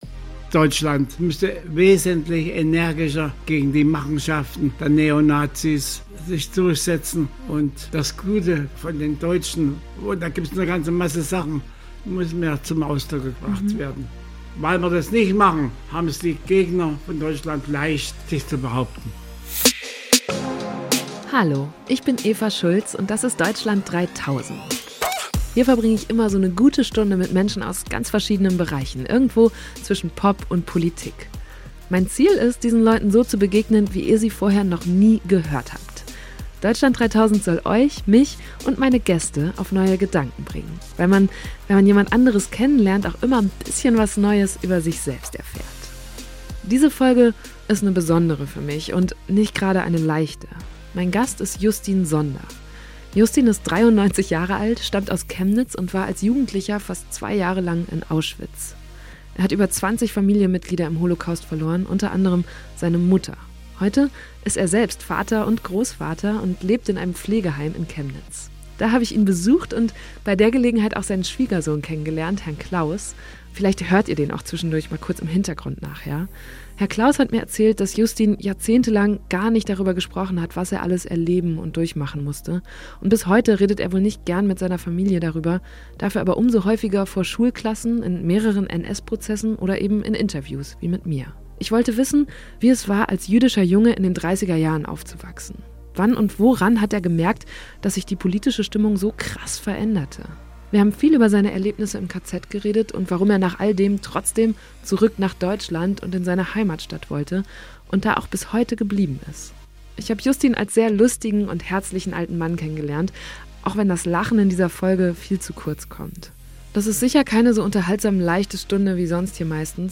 Bis heute redet er nicht gern mit seiner Familie darüber, was er alles erleben und durchmachen musste – dafür umso häufiger vor Schulklassen, in mehreren NS-Prozessen oder eben in Interviews, wie mit mir. Ich wollte wissen, wie es war, als jüdischer Junge in den 30er Jahren aufzuwachsen.
Das ist sicher keine so unterhaltsam-leichte Stunde wie sonst hier meistens, aber für mich ist es trotzdem, auf ihre ganz eigene Art, eine gute Stunde geworden, und eine sehr wertvolle.